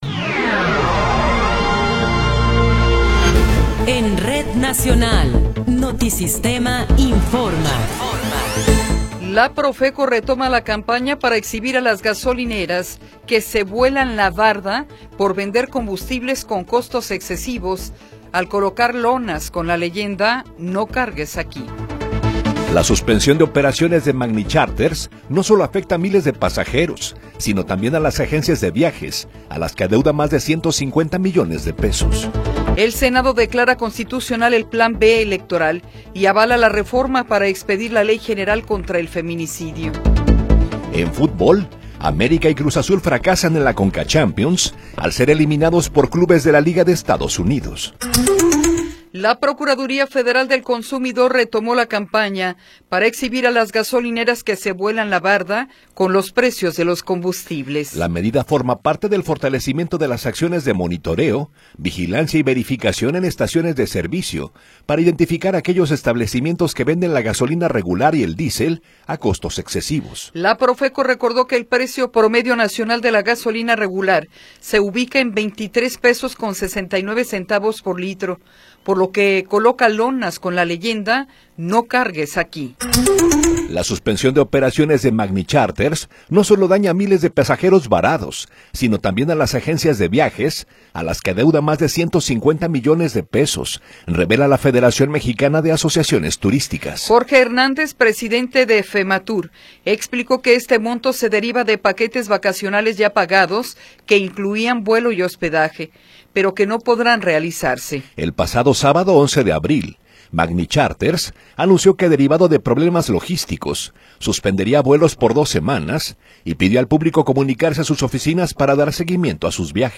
Noticiero 8 hrs. – 15 de Abril de 2026
Resumen informativo Notisistema, la mejor y más completa información cada hora en la hora.